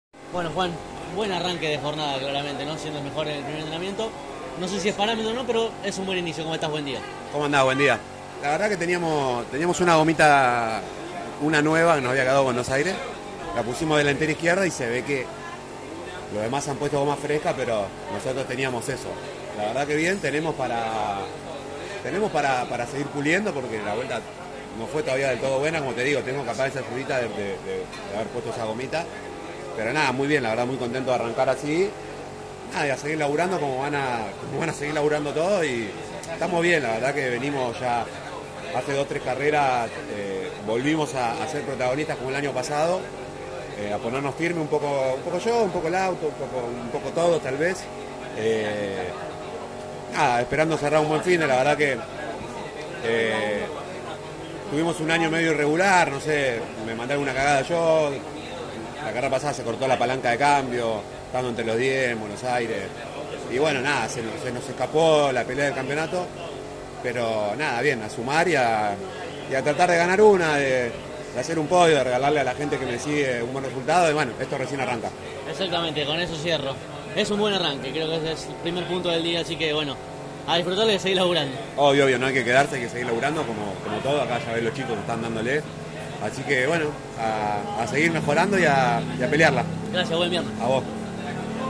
en diálogo exclusivo con CÓRDOBA COMPETICIÓN: